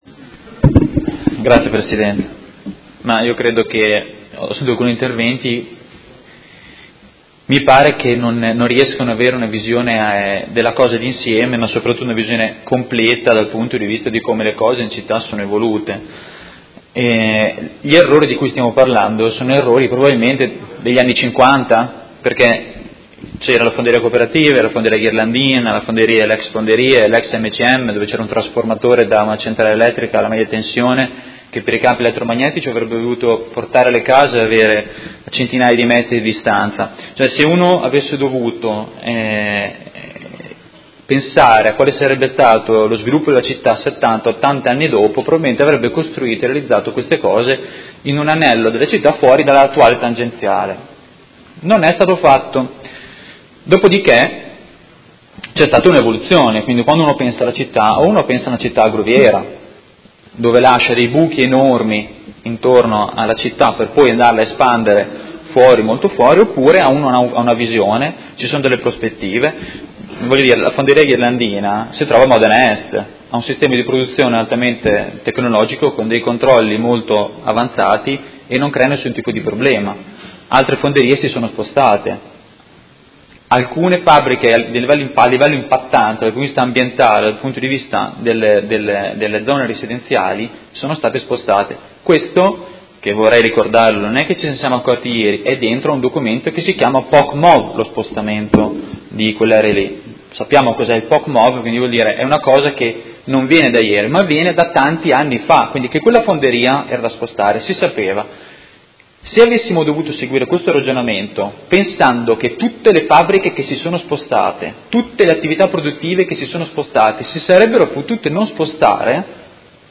Seduta del 01/02/2018 Dibattito. Interrogazione dei Consiglieri Malferrari, Cugusi e Trande (Art.1-MDP/Per Me Modena) avente per oggetto: Notizia di delocalizzazione delle Fonderie Cooperative di Modena – Primo firmatario Consigliere Malferrari.